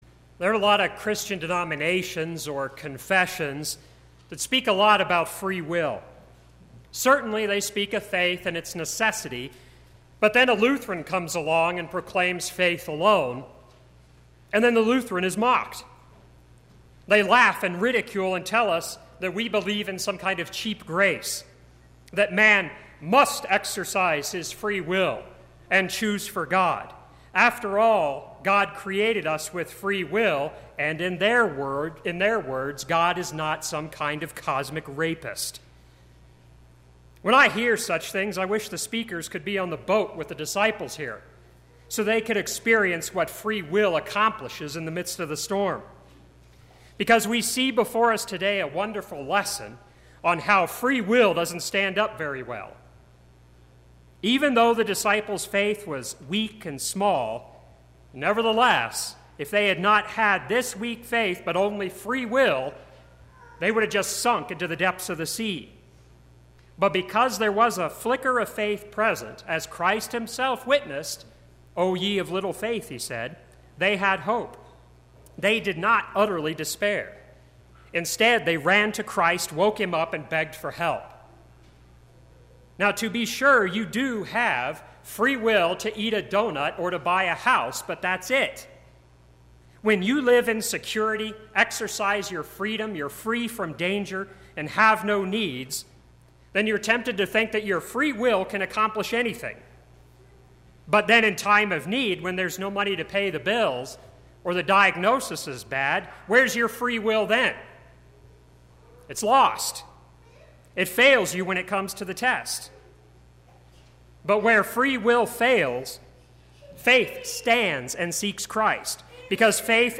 Sermon - 1/29/2017 - Wheat Ridge Lutheran Church, Wheat Ridge, Colorado
4th Sunday of Epiphany